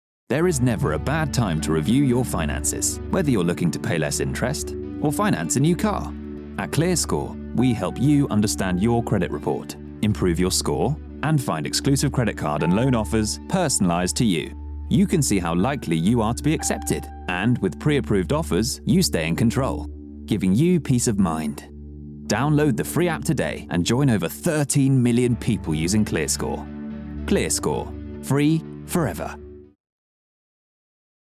Inglés (Británico)
Amable, Cálida, Natural, Llamativo, Cool
Corporativo